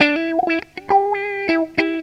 GTR 44 EM.wav